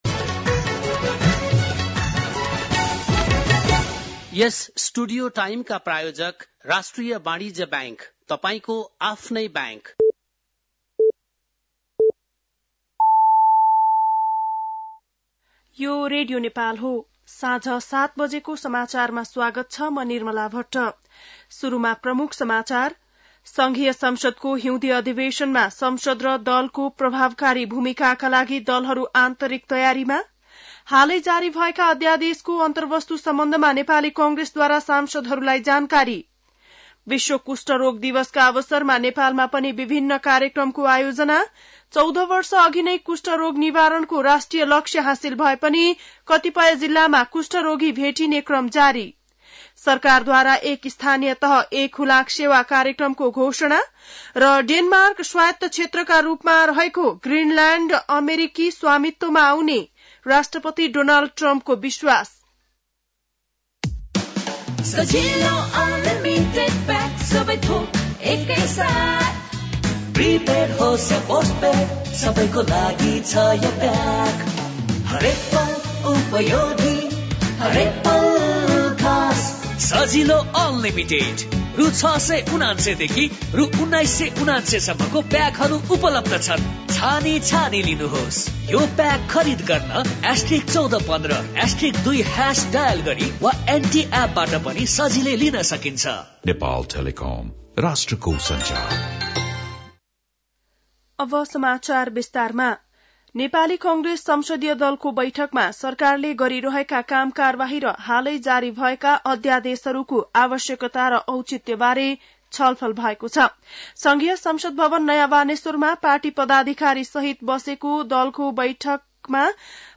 बेलुकी ७ बजेको नेपाली समाचार : १४ माघ , २०८१
7-pm-news-3.mp3